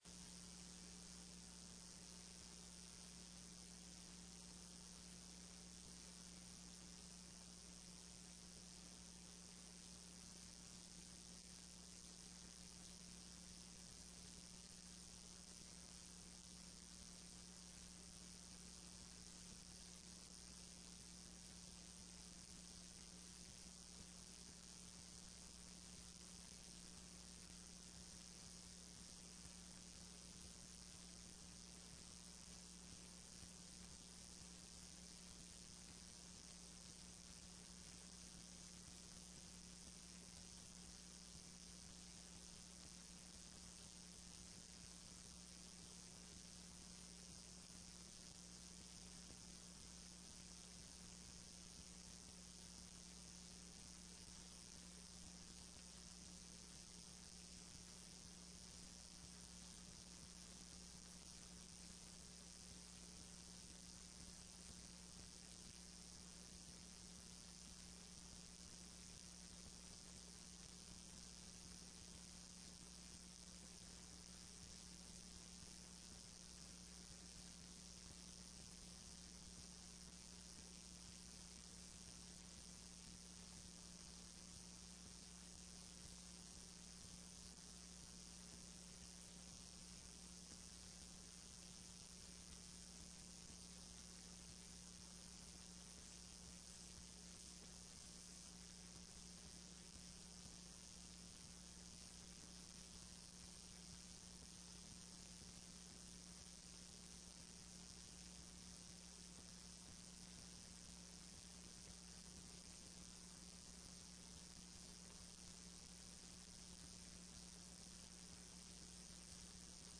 TRE-ES Sessão Plenária do dia 11/02/15